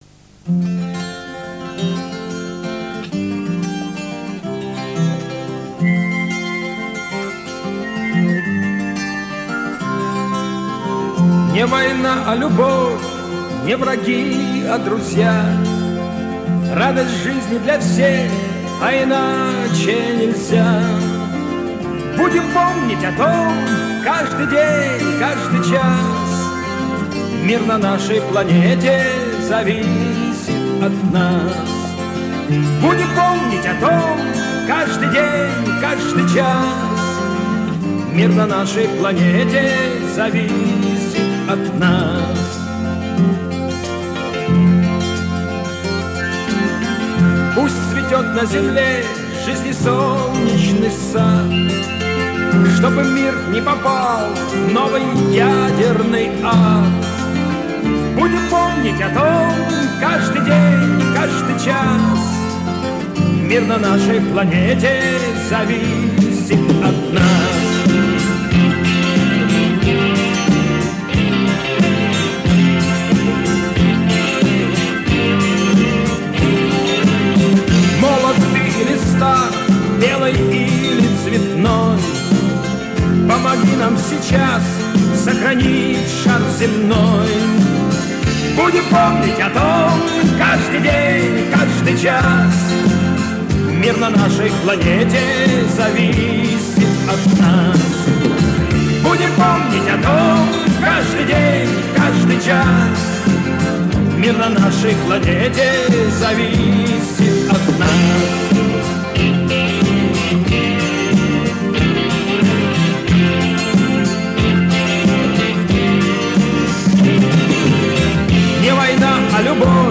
Антивоенная песня